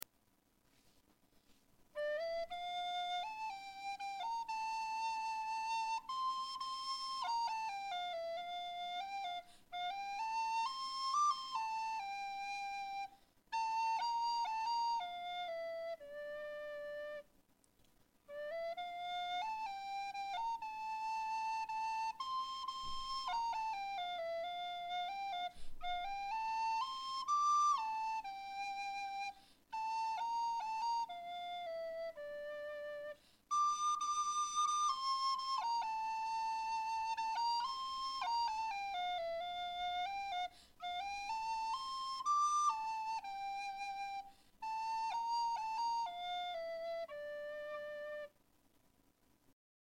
25.7.2010 בס"ד מצ"ב הקלטה שהקלטתי בביתי. בהקלטה שומעים את המנגינה לשיר לשבת "כי אשמרה שבת".
כ"ג באב תש"ע, 10:04 זהו ניגון מסורתי או לחן חדש?